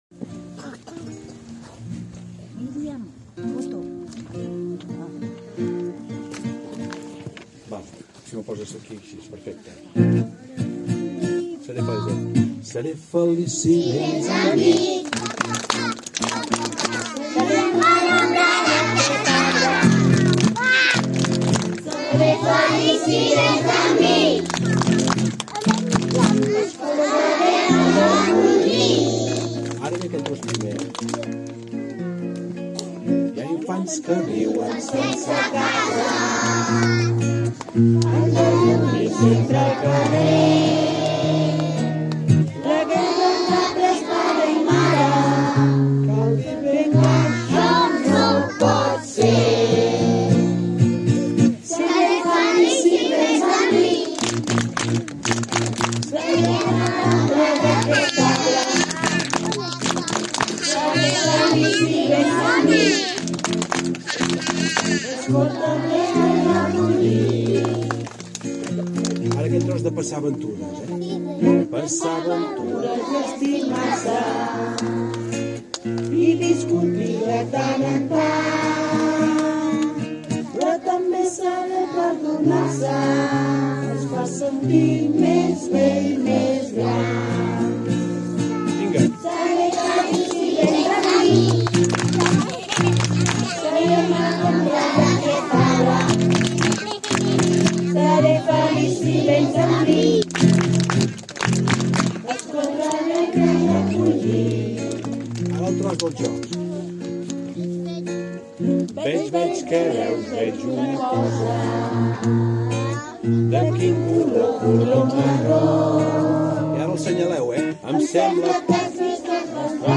Després de seure una estona a l’ombra de l’arbre i de sentir la benvinguda de la Regidora, la mainada va cantar la cançó de l’arbre.
Audio-canco-cantada-el-dia-de-la-festa-online-audio-converter.com_.mp3